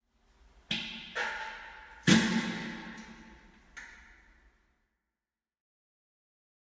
屁 " 恶魔般的龙法特
描述：真正的屁与一些自然的混响。在一个恶心的搞砸的酒吧里，用一把乱糟糟的iPhone 7录制。一如既往，我喝醉了，在那里可爱的厕所放屁。
标签： 混响 性感 环境 人类 现实生活中 放屁 声乐 恶心 万圣节 丑陋 气氛 真正
声道立体声